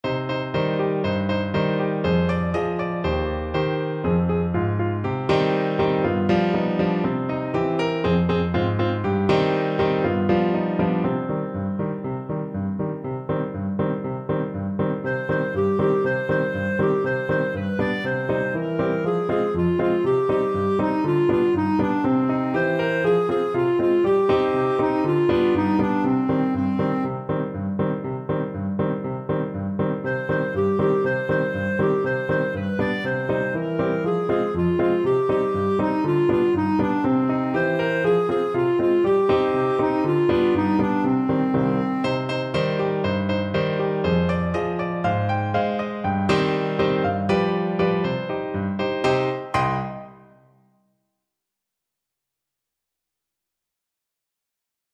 Clarinet version
2/4 (View more 2/4 Music)
C minor (Sounding Pitch) D minor (Clarinet in Bb) (View more C minor Music for Clarinet )
Allegro (View more music marked Allegro)
Classical (View more Classical Clarinet Music)